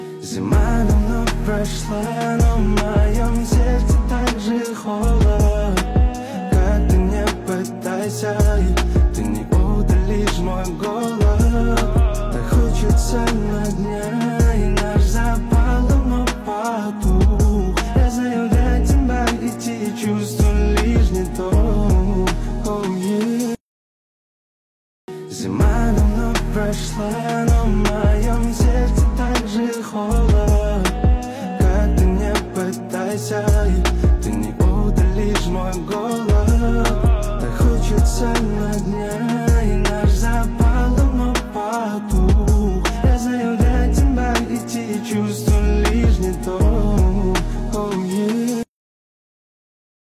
Жанр: Казахские / Русские песни